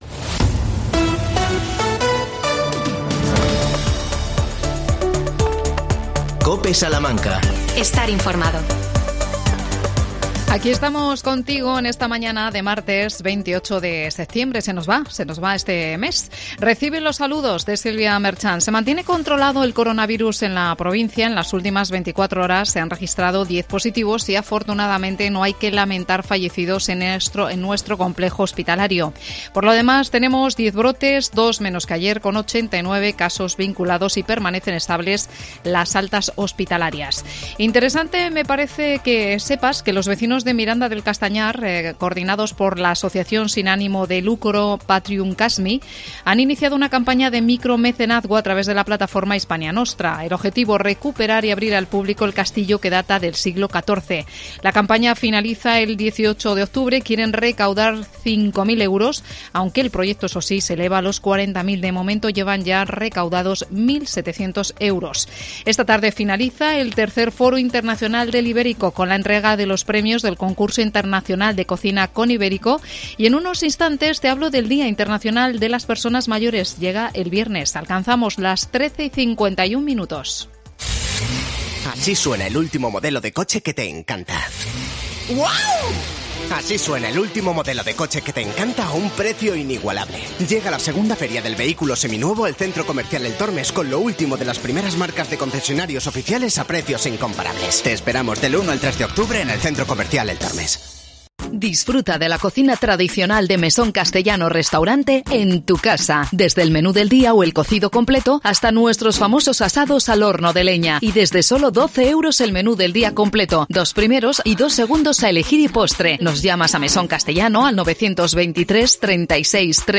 AUDIO: 1 de octubre, Día Internacional de las Personas Mayores. Entrevistamos